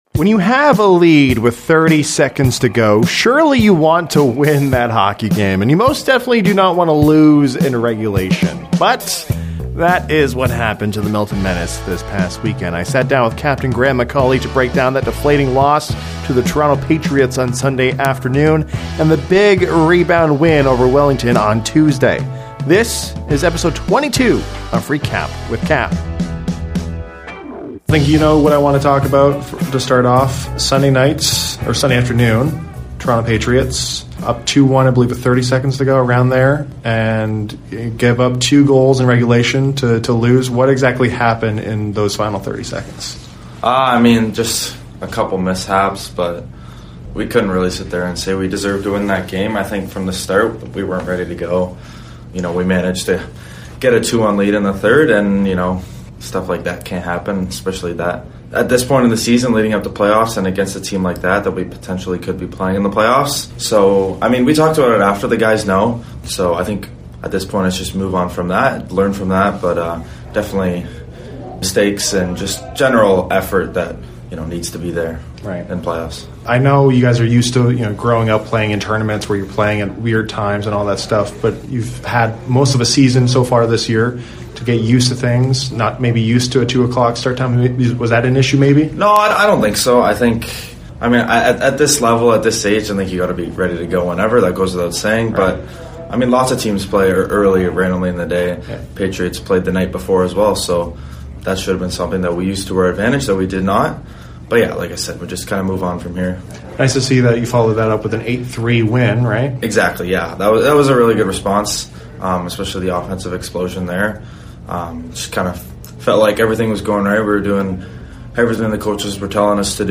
RECAP WITH CAP: Weekly interview